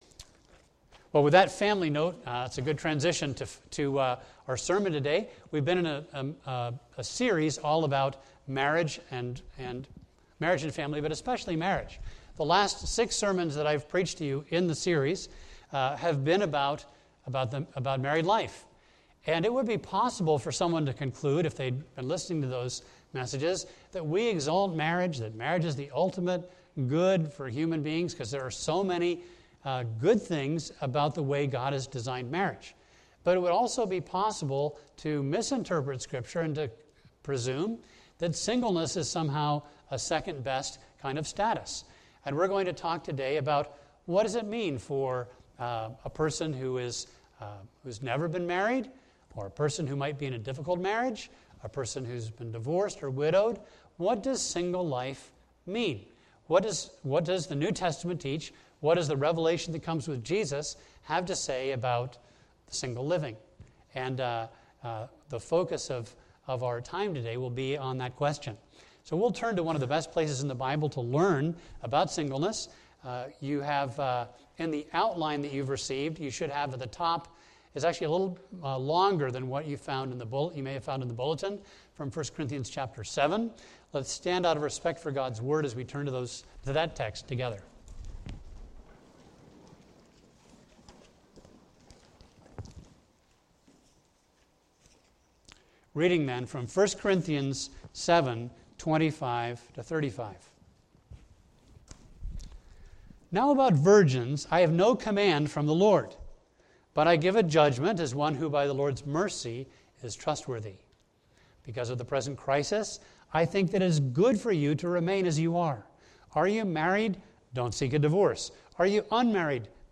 A message from the series "The Meaning of Marriage."